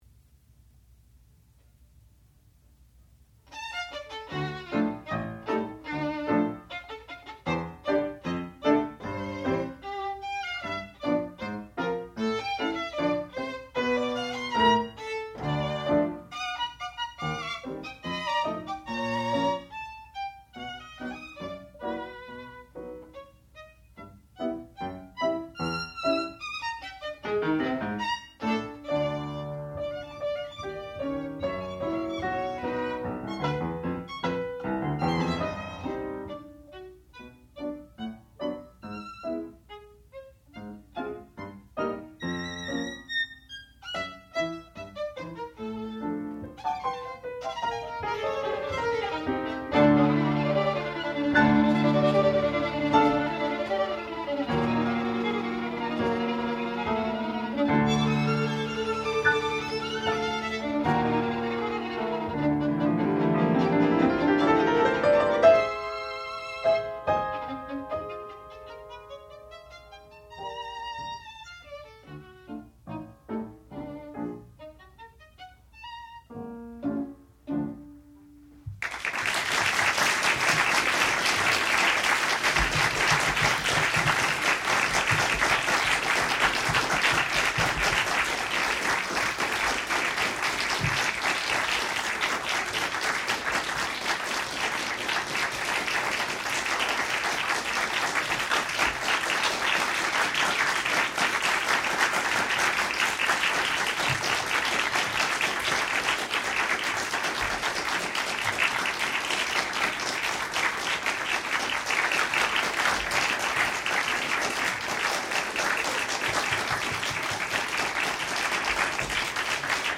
classical music
violin
piano
Master's Recital